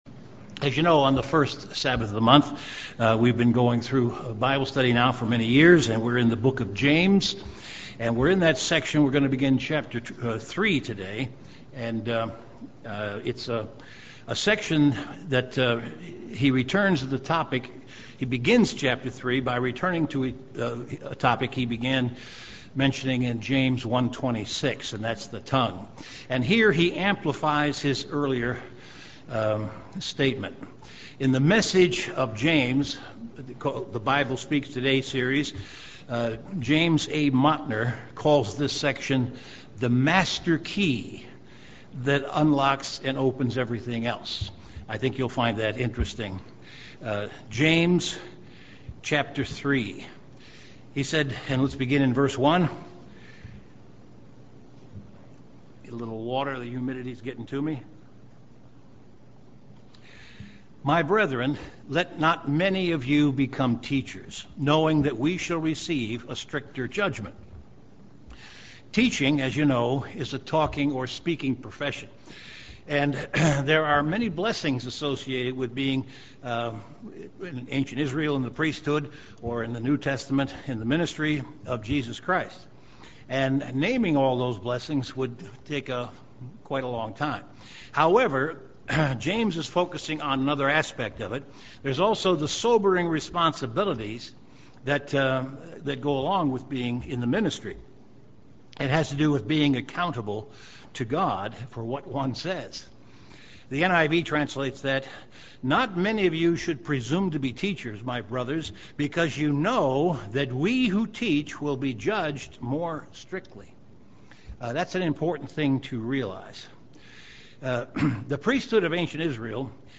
The first installment of an in-depth Bible study on the book of James chapter 3.
Given in Chicago, IL
UCG Sermon Studying the bible?